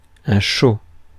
Ääntäminen
Ääntäminen France: IPA: /ʃo/ Haettu sana löytyi näillä lähdekielillä: ranska Käännöksiä ei löytynyt valitulle kohdekielelle.